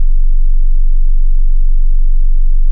64 slices, 2048 samples length of a 23.4375 Hz sine wave.